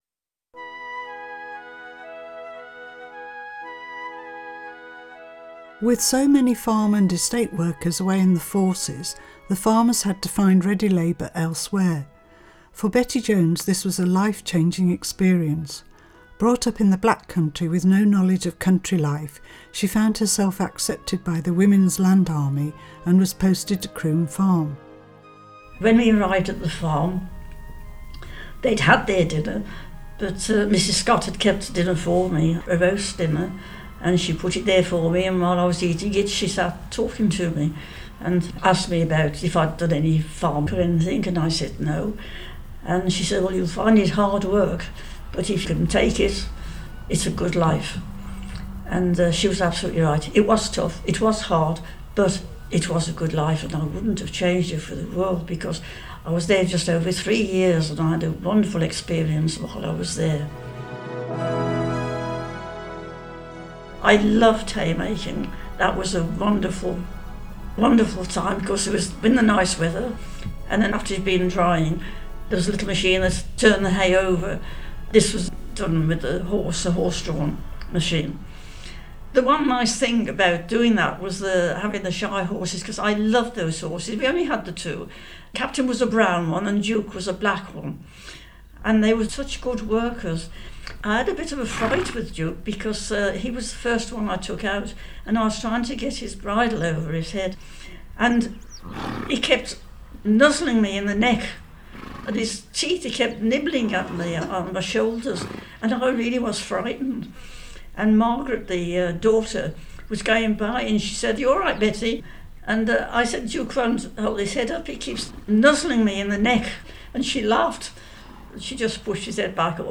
Oral History Project...